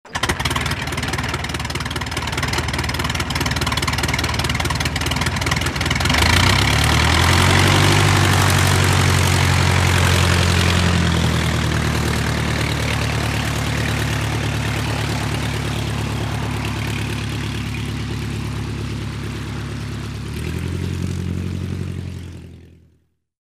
Звуки крушения самолёта, взрыва
Биплан взлетает и улетает со звуком